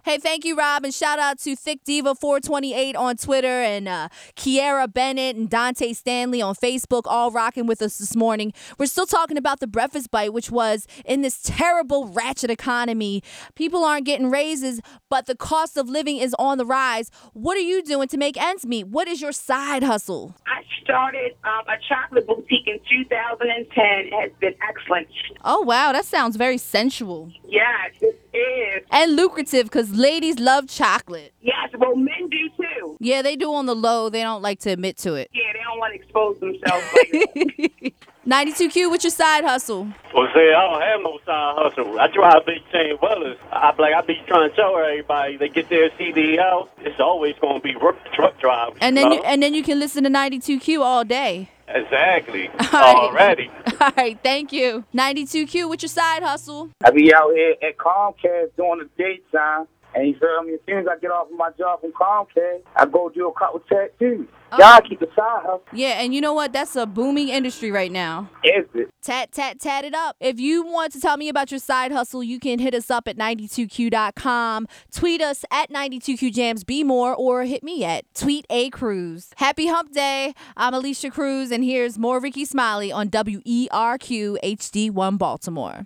Here’s what some callers had to say…..